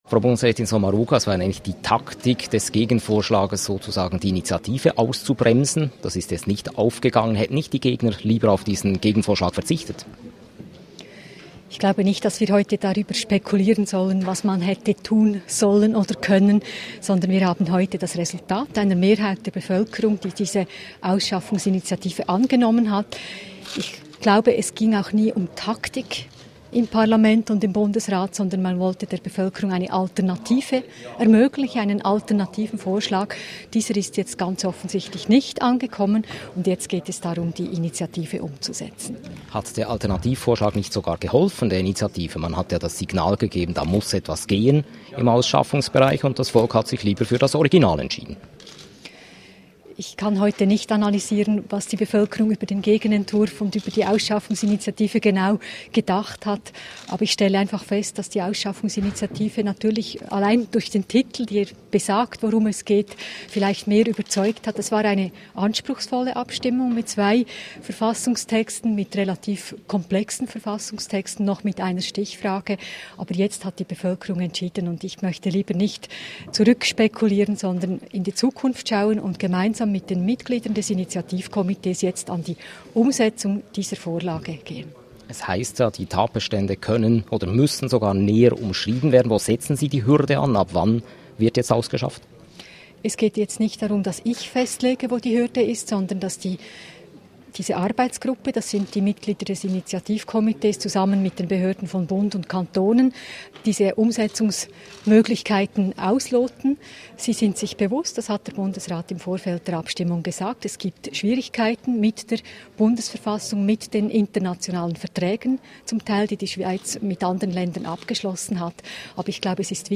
Interview mit Bundesrätin Sommaruga
Die Ausschaffungs-Initiative der SVP ist vom Stimmvolk angenommen worden, nun muss der Verfassungsartikel umgesetzt werden. Dazu ein Interview mit Justizministerin Simonetta Sommaruga.